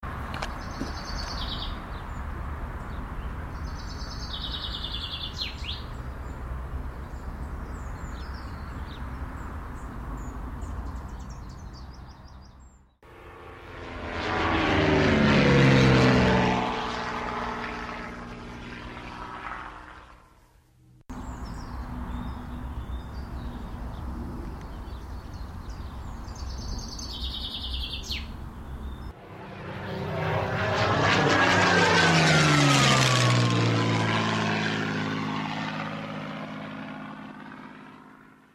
Les oiseaux et les avions de tourisme nous accompagnent aujourd’hui, enregistrement sonore ci-joint.
Ambiance sonore de la balade en